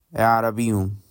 Arabs (Arabic: عَرَب, DIN 31635: ʿarab, Arabic: [ˈʕɑ.rɑb] ; sg. عَرَبِيٌّ, ʿarabiyyun, pronounced [ʕɑ.rɑˈbɪj.jʊn]
Ar-Arabiyyun.ogg.mp3